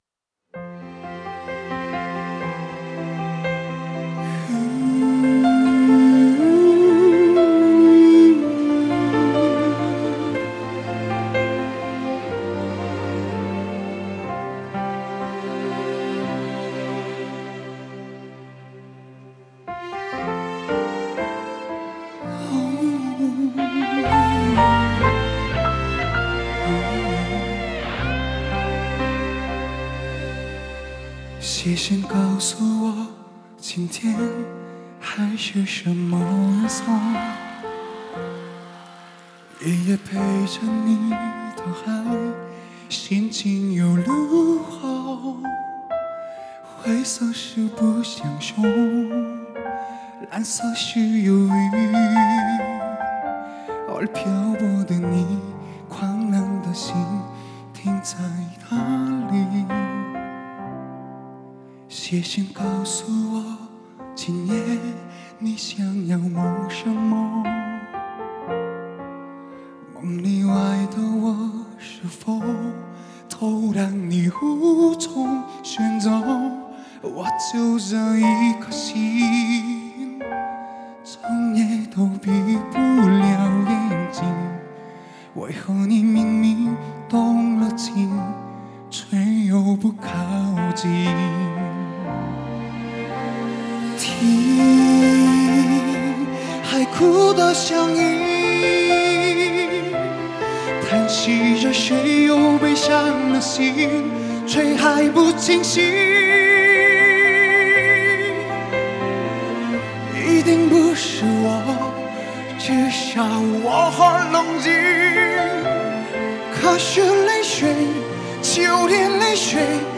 Ps：在线试听为压缩音质节选，体验无损音质请下载完整版
(Live)